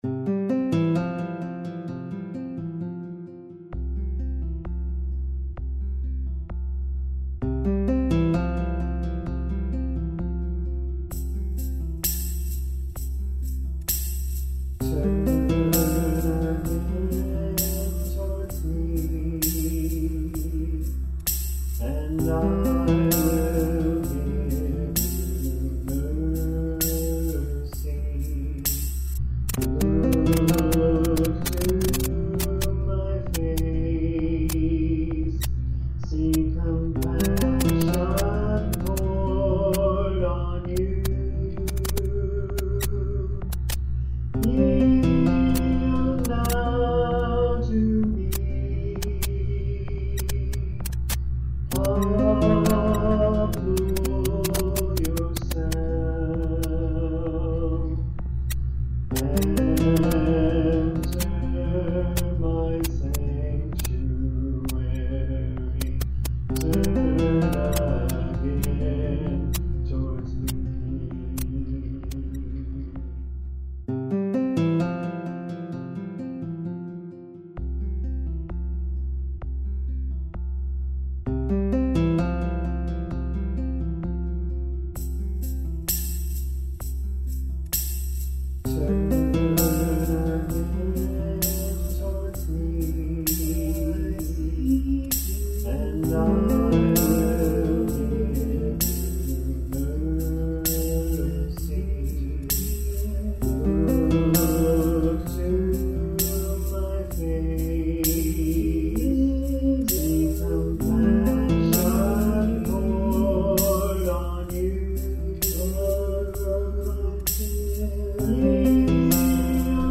extreme low tones and bass ... and these will not play